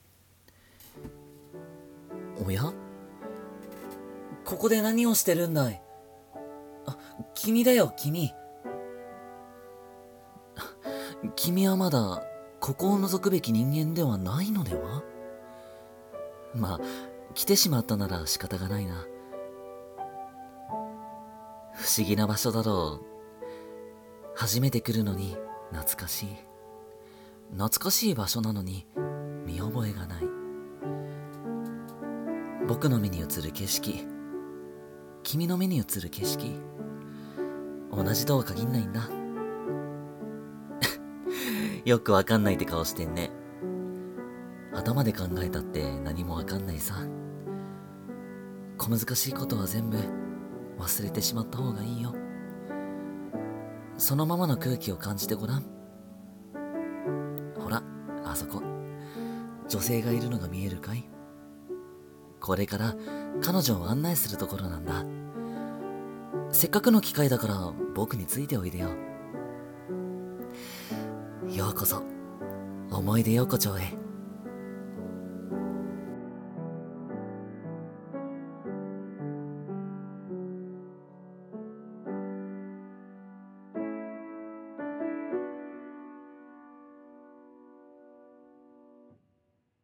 【1人声劇】想い出横丁